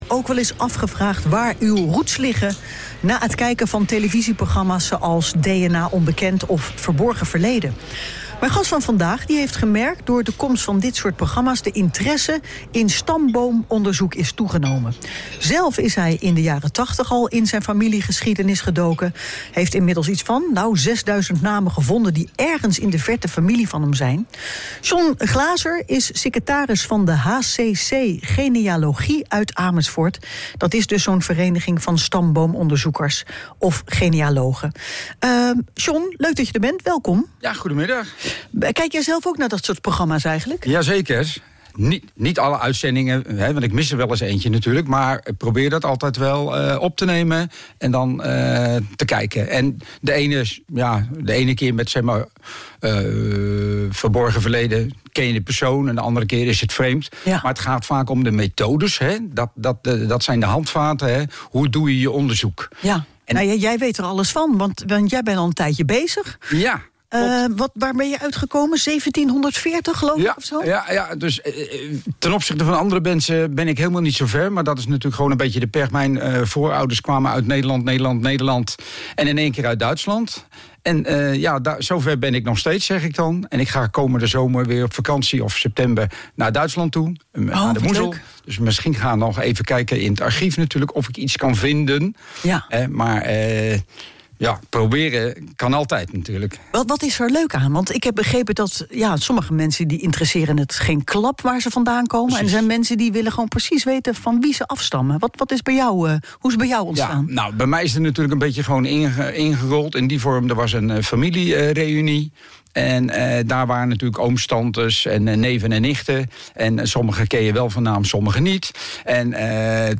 HCC!genealogie op de radio
Radio M is een onderdeel van RTV Utrecht. Dagelijks is daar het smakelijke lunchprogramma 'Aan Tafel' dat wordt gelardeerd met gevarieerde muziek.